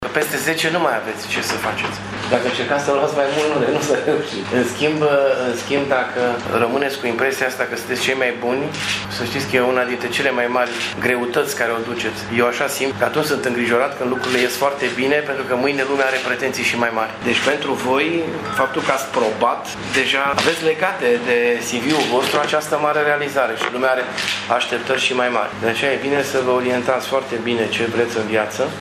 Cei şase elevi au primit, astăzi, un premiu de 500 de lei, într-un cadru festiv.
Primarul Dorin Florea i-a felicitat pe tineri, dar i-a avertizat că rezultatul lor îi obligă să fie mai responsabili: